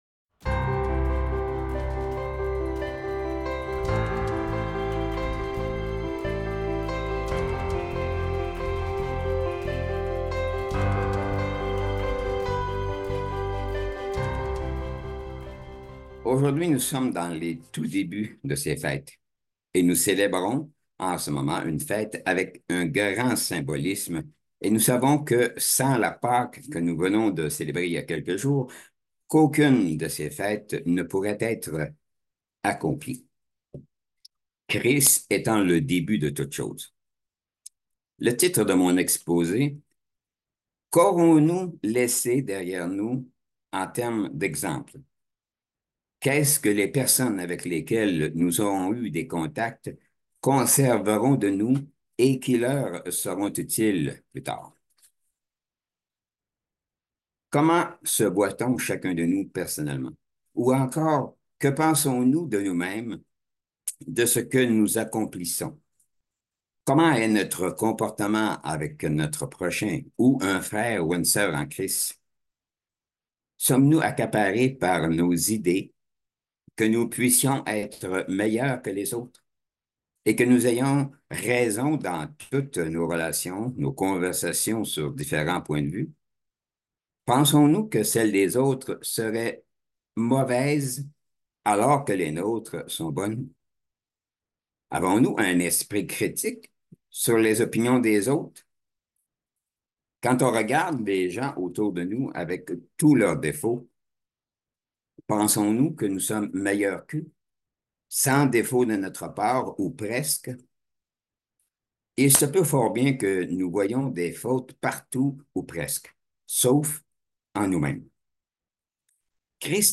Le sermon met l'accent sur l'humilité, l'autocritique et le désir d'amélioration continue, en se demandant comment nous pouvons laisser un héritage positif à travers nos actions et nos paroles.
Given in Bordeaux